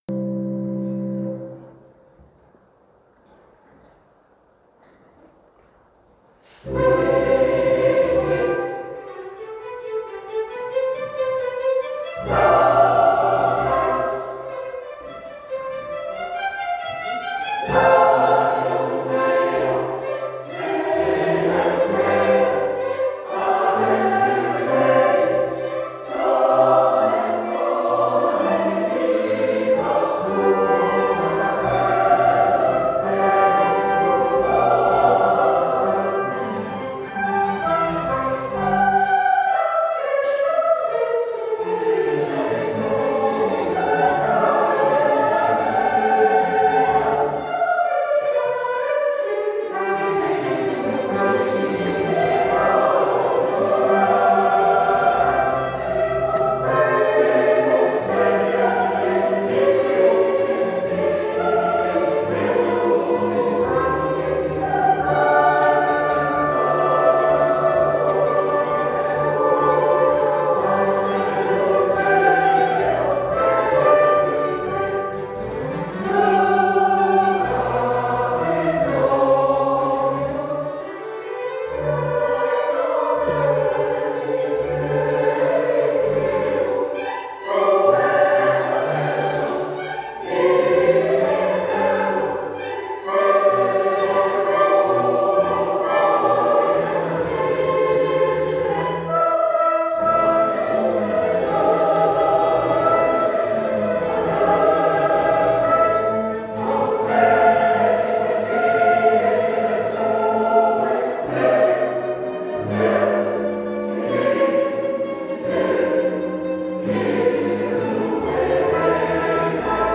geluidsopname_concert_2024.mp3